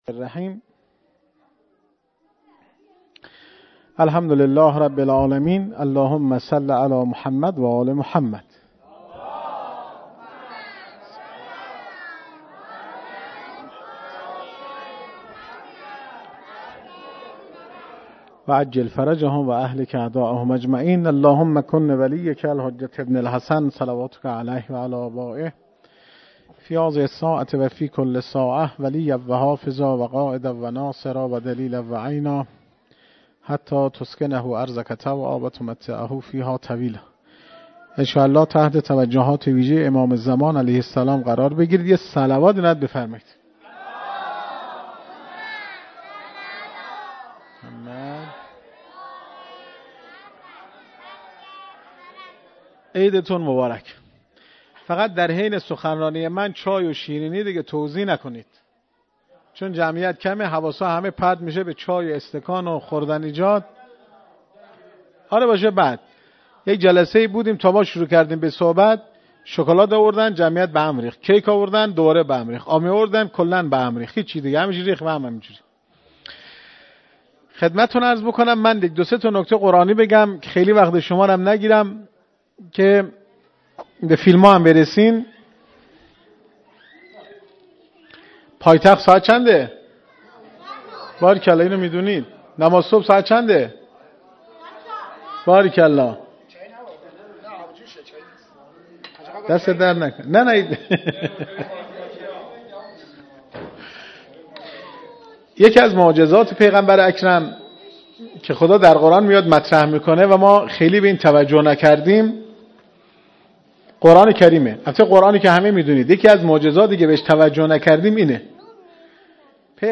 سخنرانی
مراسم جشن سالروز میلاد پیامبر اکرم(ص) و امام جعفر صادق(ع).mp3